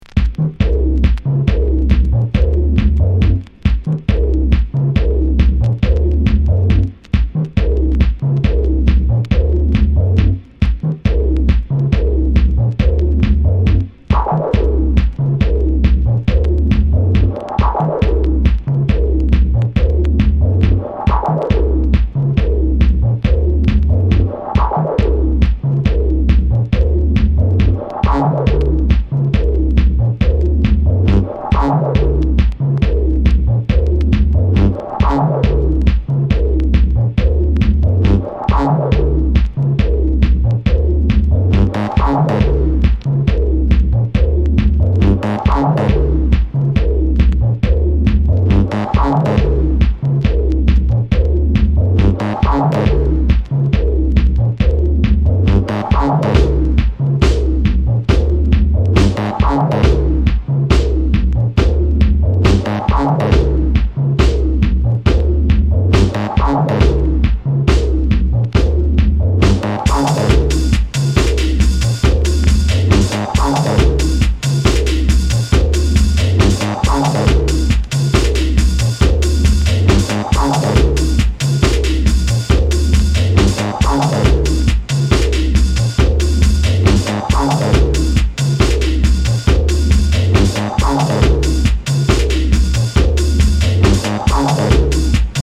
ディープテクノミニマル
くぐもったグルーヴで浮き沈みする